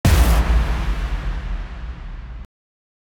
explosion_sound.wav